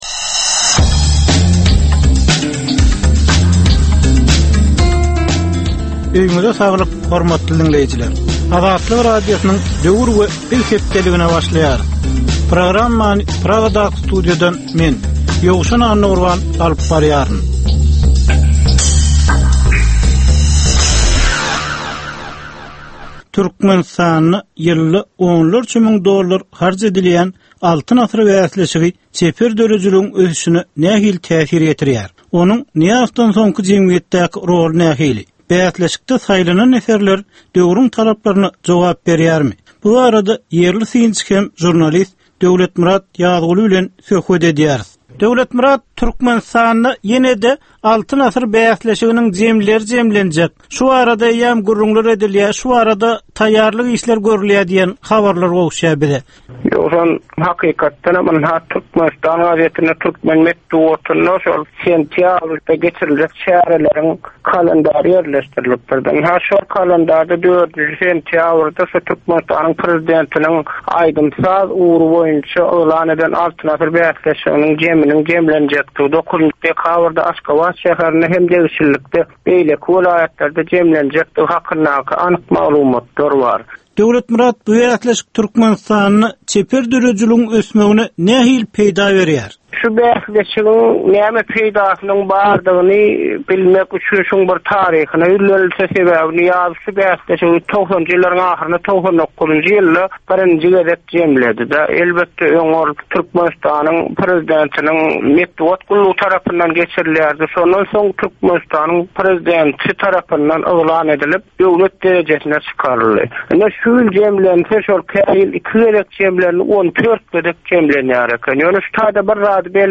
Türkmen jemgyýetindäki döwrüň meseleleri. Döwrüň anyk bir meselesi barada 10 minutlyk ýörite syn-gepleşik. Bu gepleşikde diňleýjiler, synçylar we bilermenler döwrüň anyk bir meselesi barada pikir öwürýärler, öz garaýyşlaryny we tekliplerini orta atýarlar.